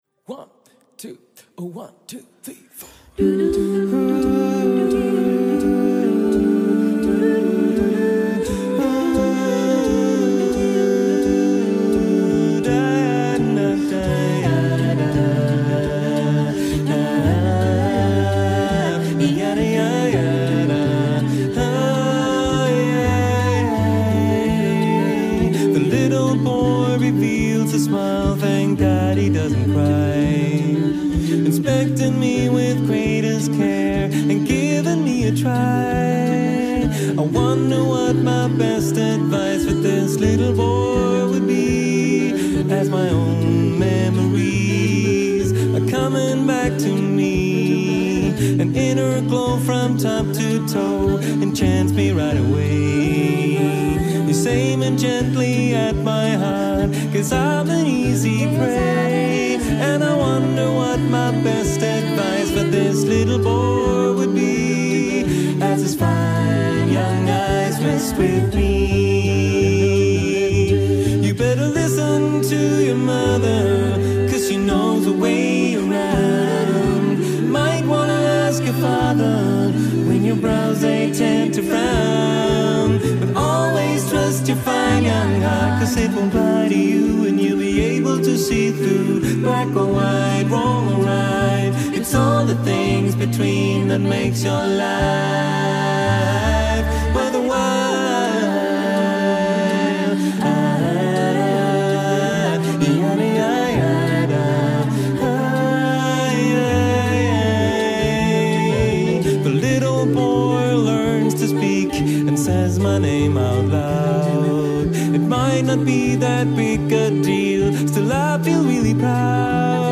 Voicing: SATBB a cappella